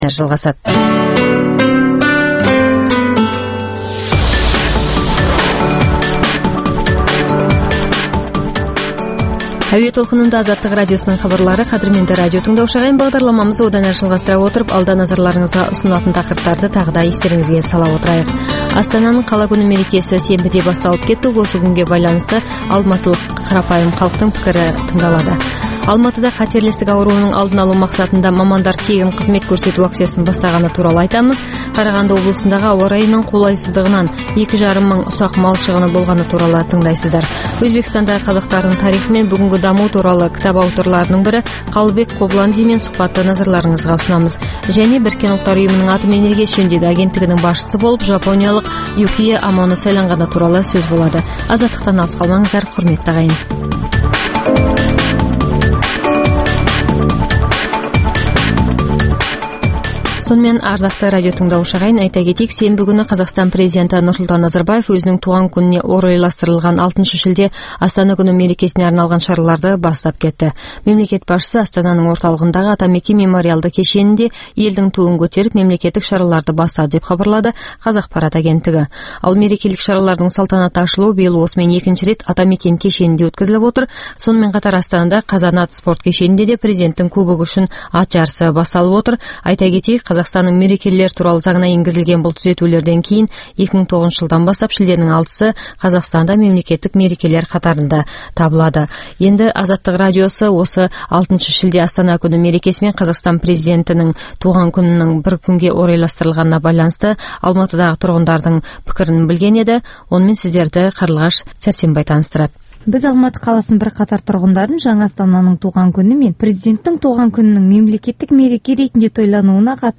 Бүгінгі күннің өзекті тақырыбына талқылаулар, оқиға ортасынан алынған репортаж, пікірталас, қазақстандық және халықаралық сарапшылар пікірі.